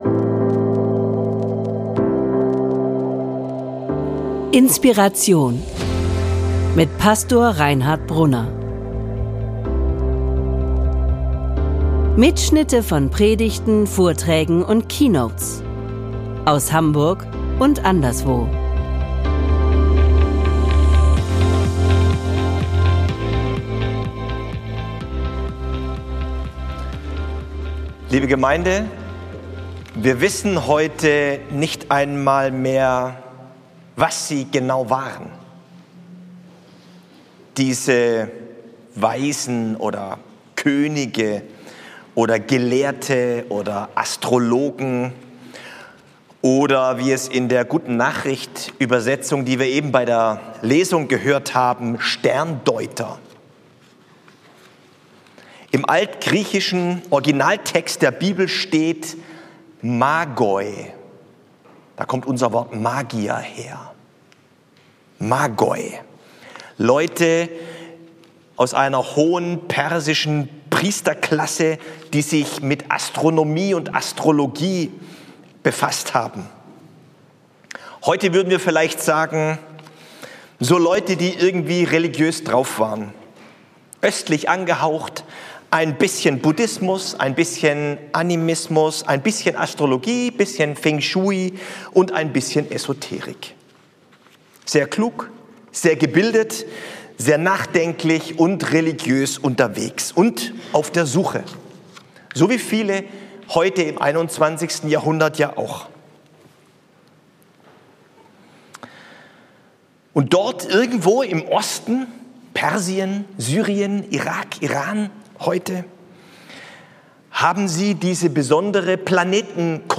Der Weg ist nicht das Ziel. Das Ziel ist das Ziel - Predigt vom Heiligabend 2024 ~ INSPIRATION - Predigten und Keynotes Podcast